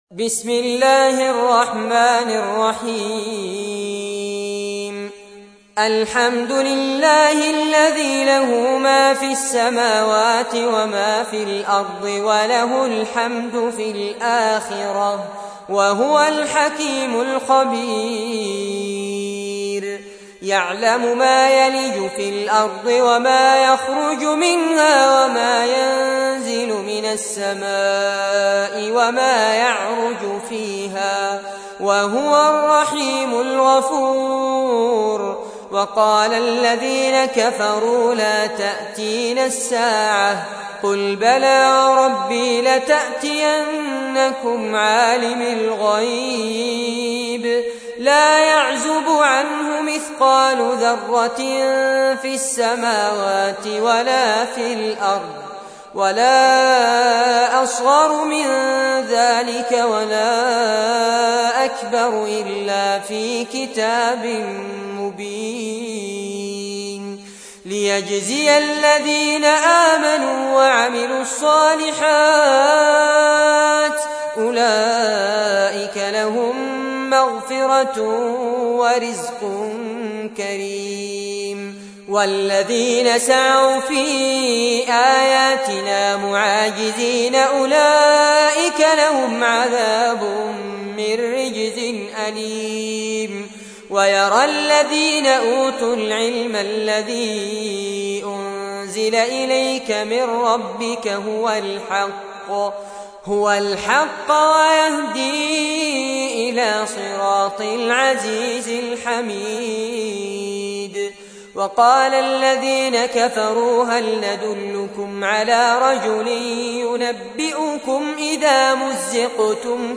تحميل : 34. سورة سبأ / القارئ فارس عباد / القرآن الكريم / موقع يا حسين